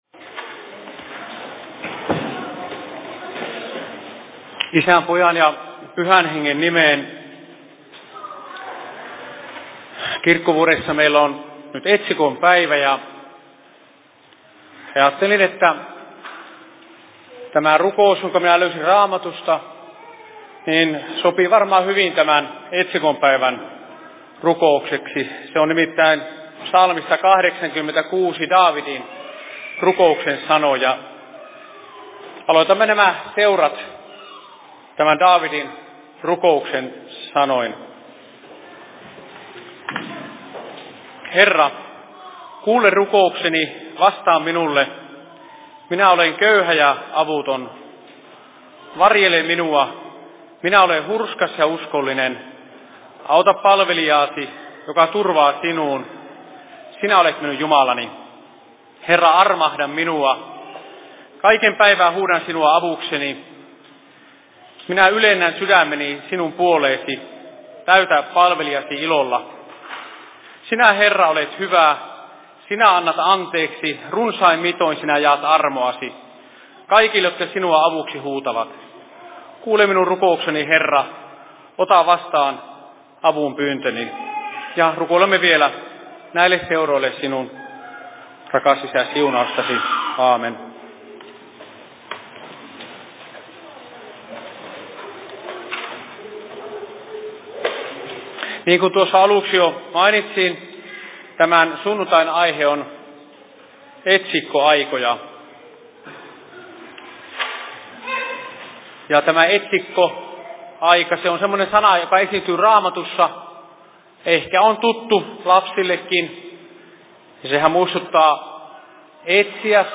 Seurapuhe Kajaanin RY:llä 21.08.2022 15.57
Paikka: Rauhanyhdistys Kajaani